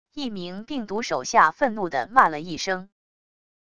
一名病毒手下愤怒的骂了一声wav音频